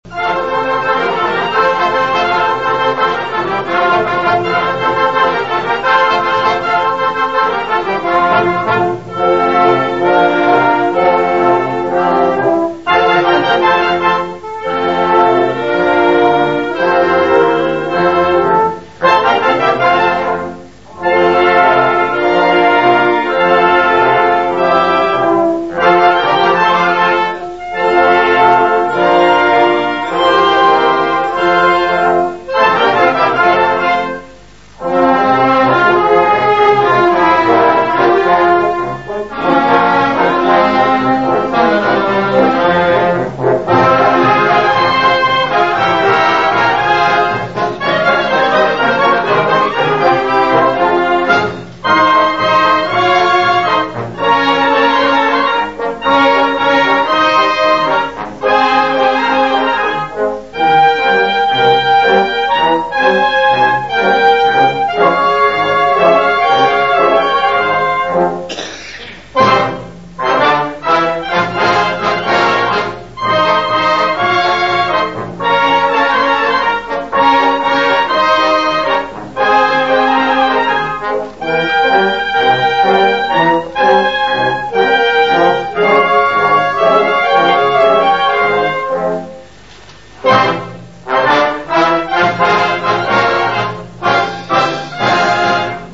Ascolta Registrazione dal vivo anni 80 Teatro Oriente - Torre del Greco Se non si ascolta subito la musica attendere qualche secondo solo la prima volta.